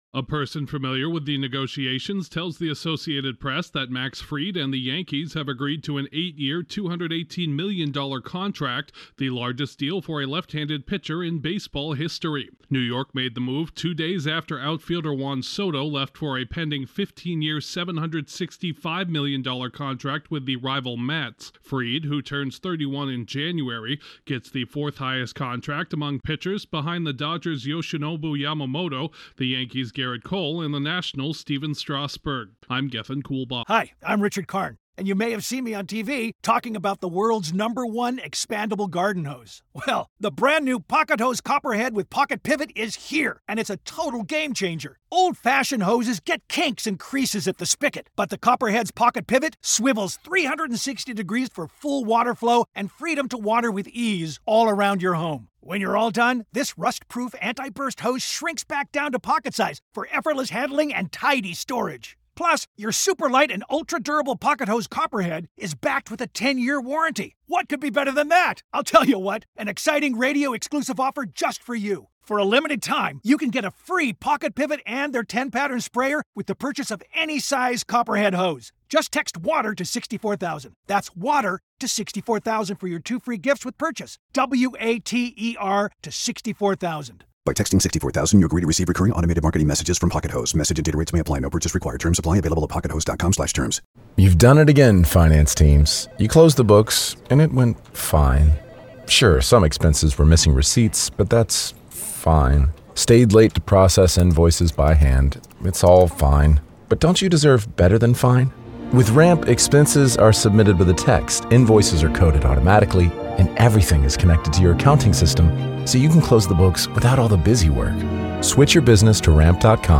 The Yankees have signed a record deal with a star pitcher after losing out on baseball's top free agent. Correspondent